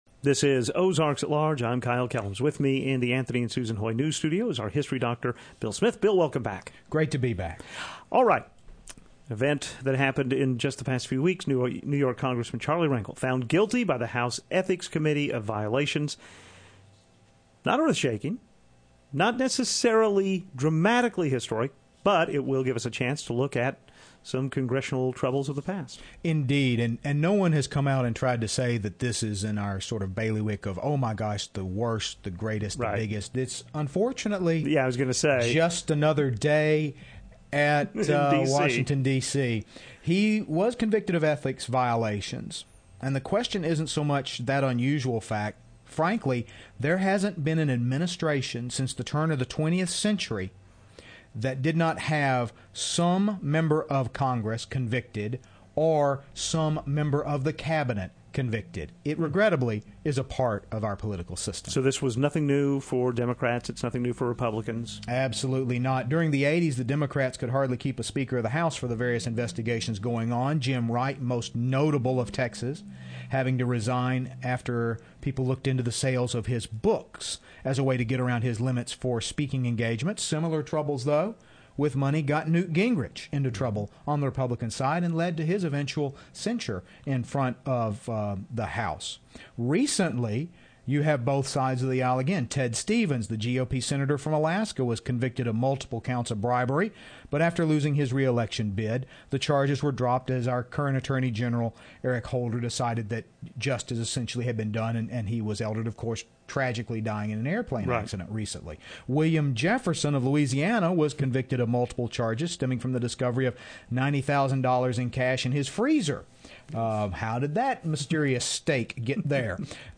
We get another call from our history doctor, this time to look back at past Congressional problems.